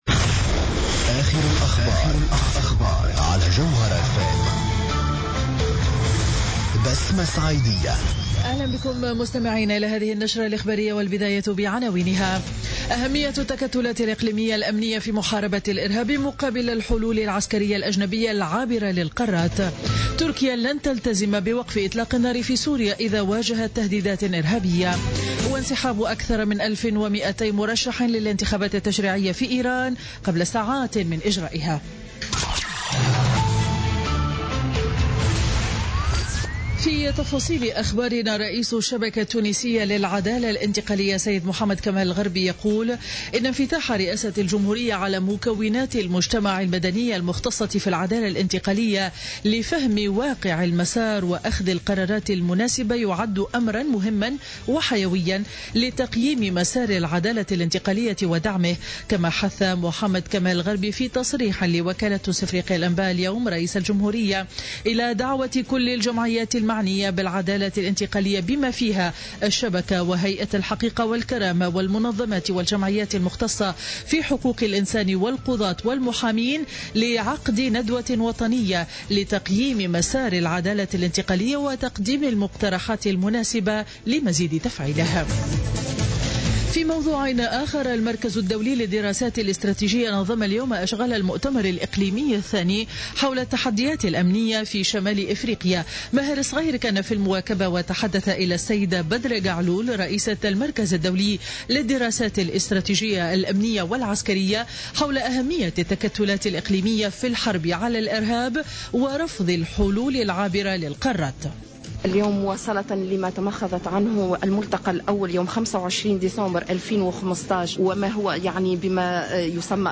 نشرة أخبار منتصف النهار ليوم الخميس 25 فيفري 2016